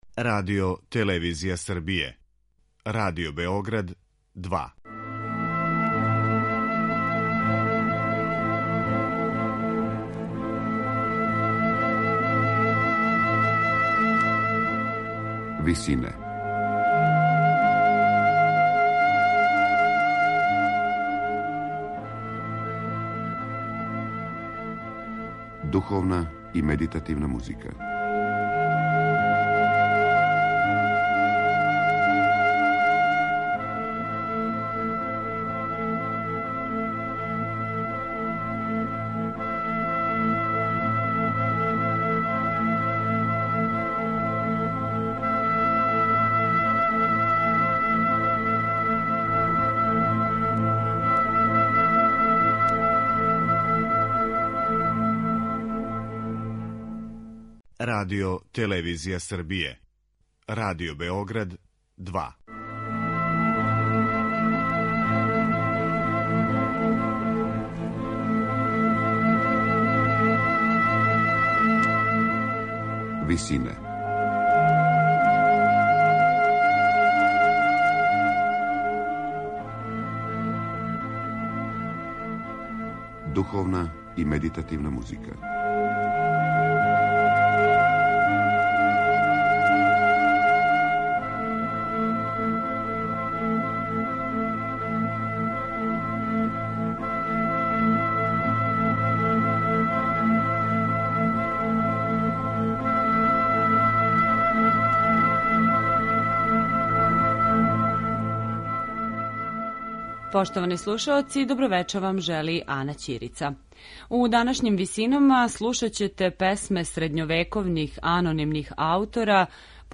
Емитоваћемо неколико песама које су средњовековни анонимни аутори посветили Девици Марији.